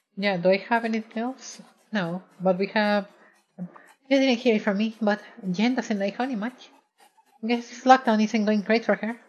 So recently I recorded a video in OBS and I had an issue with my microphone track, where it blended the game audio track inside, and I’m not sure how to fix it, I tried using the “Isolate vocals” effect, but it’s really, really bad.